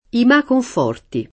malo [m#lo] agg.